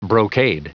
Prononciation du mot brocade en anglais (fichier audio)